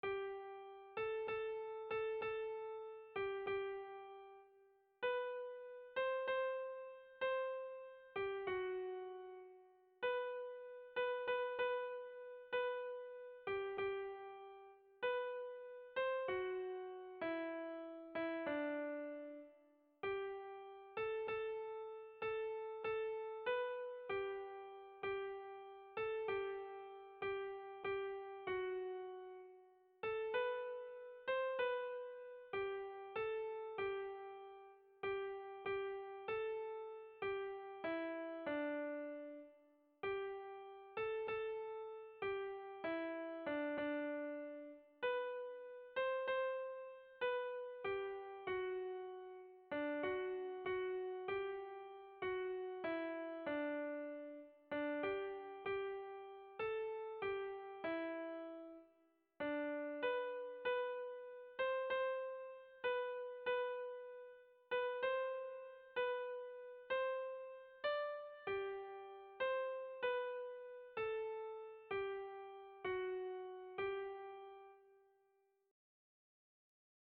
Kontakizunezkoa
ABDEF